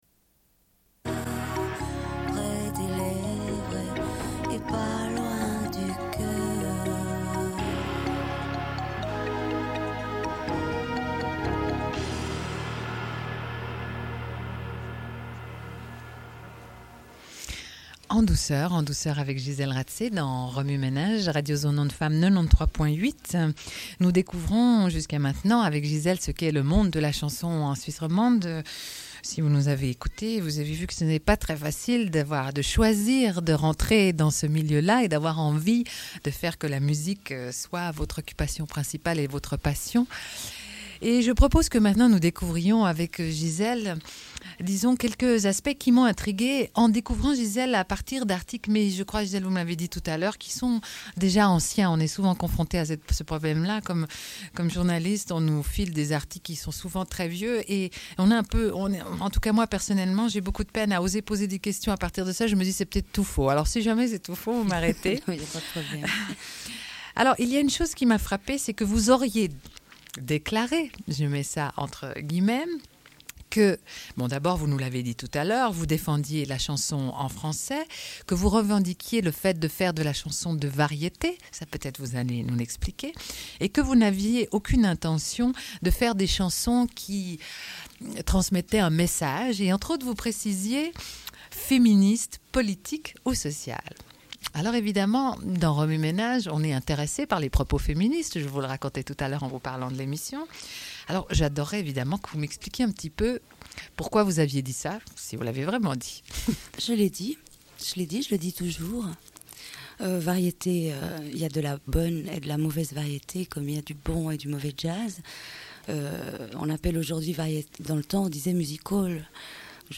Une cassette audio, face B28:49